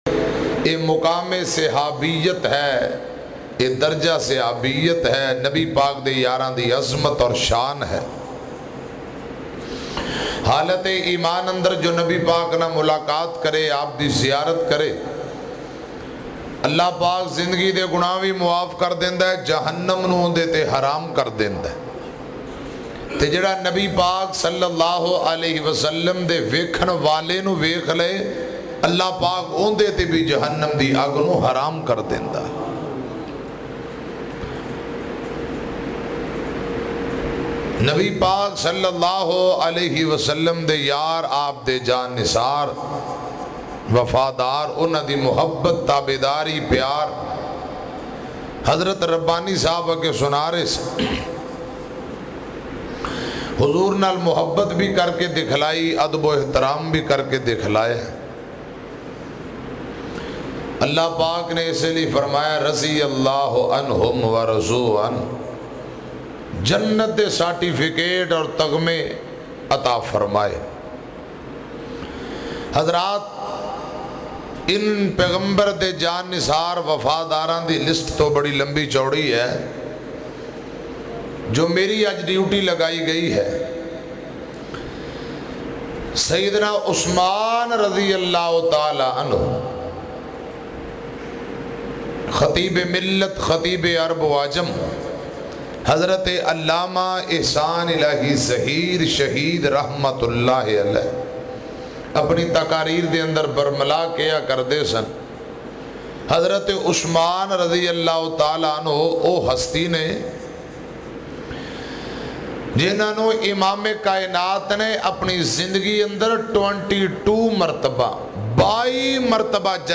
Shahadat E Usman Ghani bayan mp3